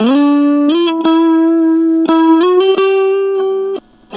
Track 02 - Guitar Lick 06.wav